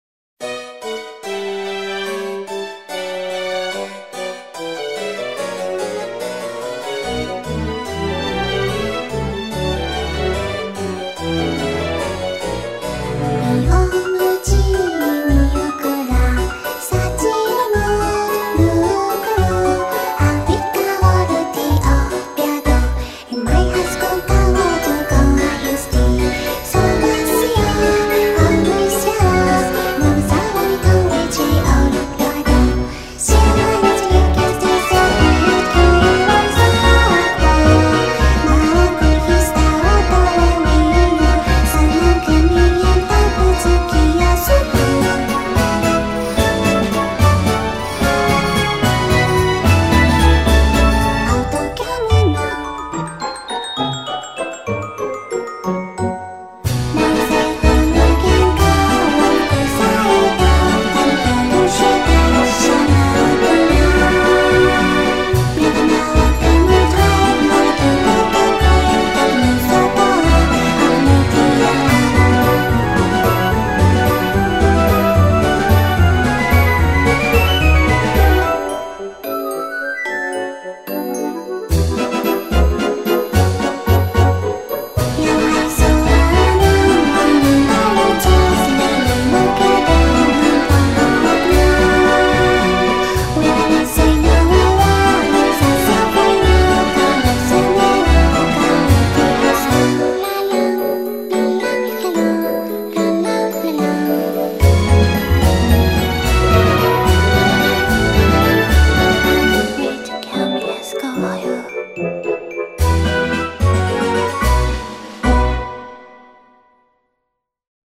BPM72-220
Audio QualityPerfect (High Quality)